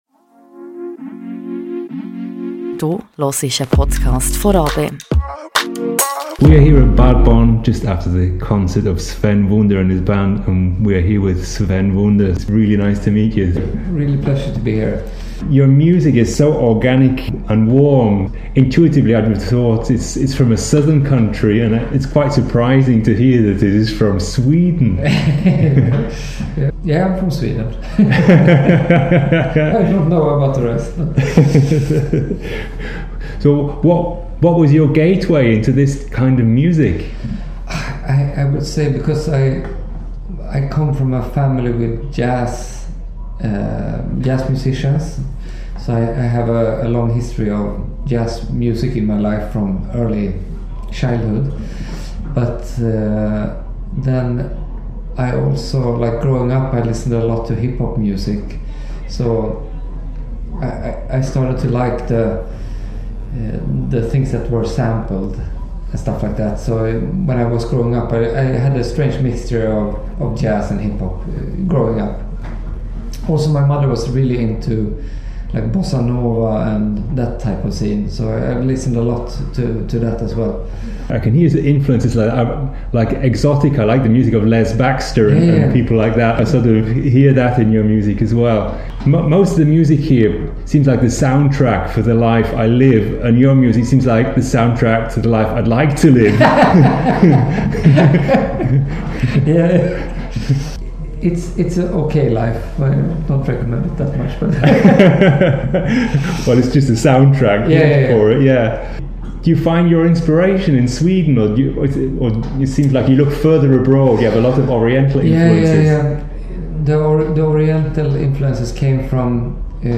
Amplifier-Interview
backstage and talked about music, music creation and music